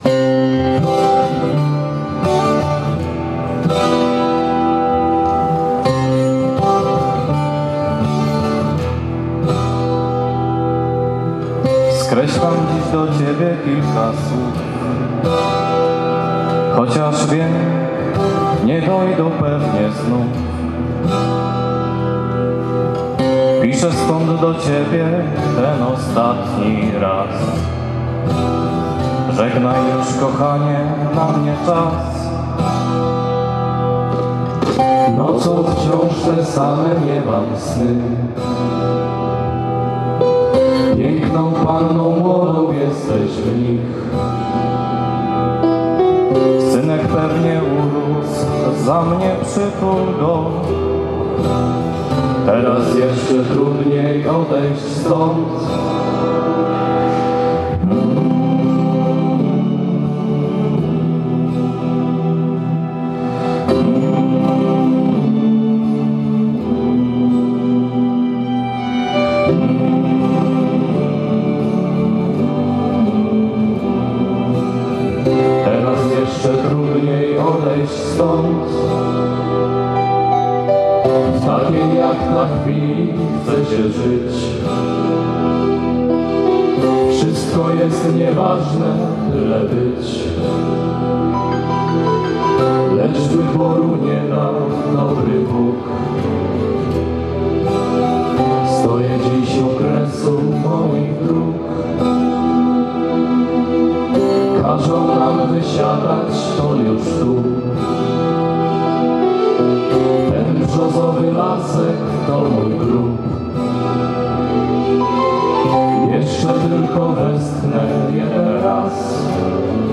81. rocznica zbrodni katyńskiej. Uroczystości w Przemyślu.
Na zakończenie zespół wokalny z 5 batalionu strzelców podhalańskich zaśpiewał pieśń „Katyń”